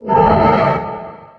dc0f4c9042 Divergent / mods / Soundscape Overhaul / gamedata / sounds / monsters / poltergeist / idle_2.ogg 26 KiB (Stored with Git LFS) Raw History